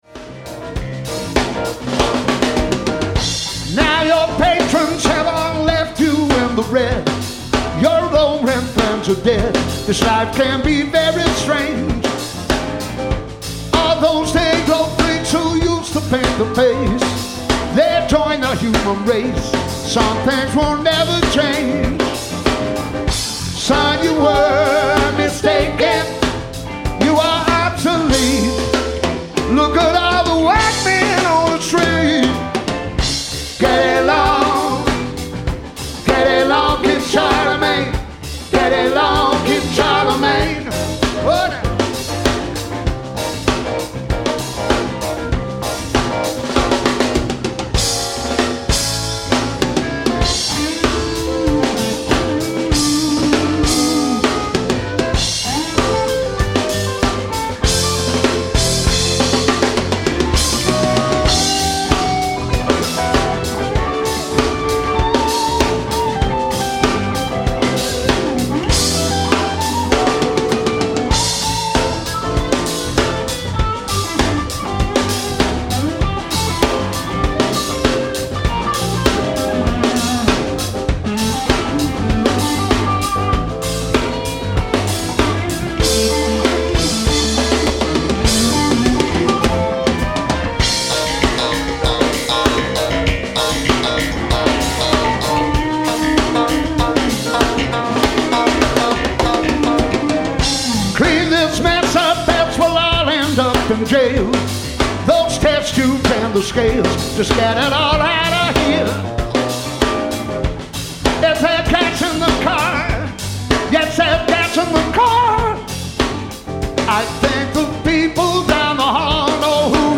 all songs recorded live 2009